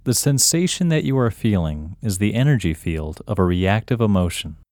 OUT – English Male 2